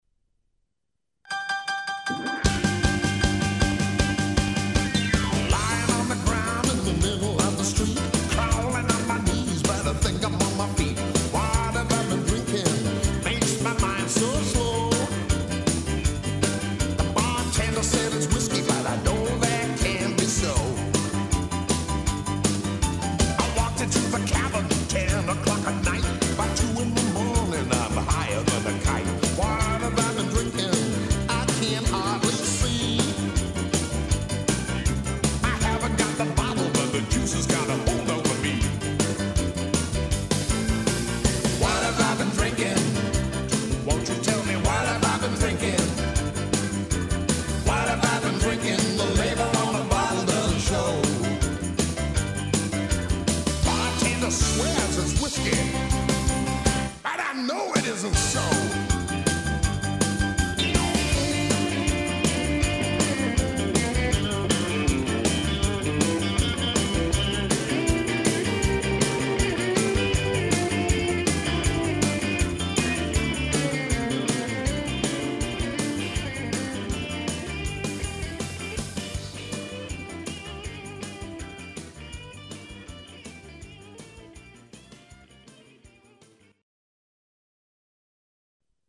gritty Blues songs